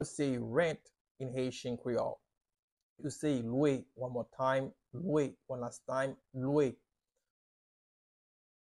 Listen to and watch “Lwe” audio pronunciation in Haitian Creole by a native Haitian  in the video below:
How-to-say-Rent-in-Haitian-Creole-Lwe-pronunciation-by-a-Haitian-teacher.mp3